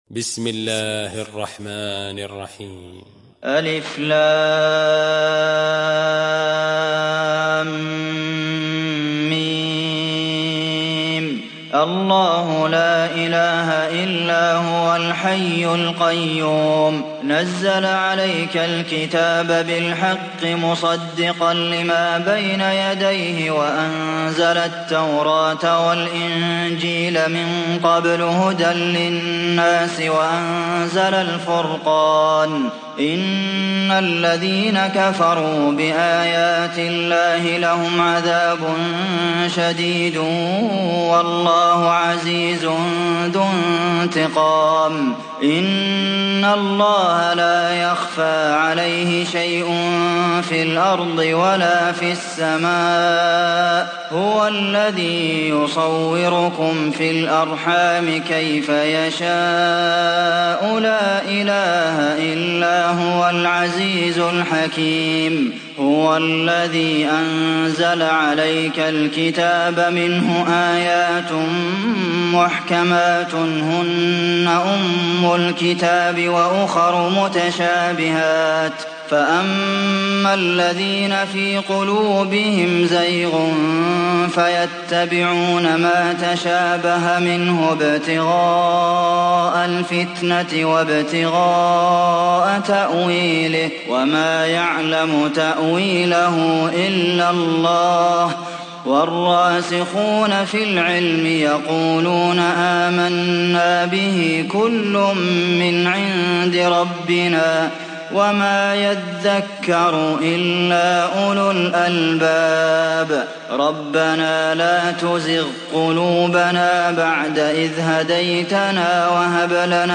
সূরা আলে ইমরান mp3 ডাউনলোড Abdulmohsen Al Qasim (উপন্যাস Hafs)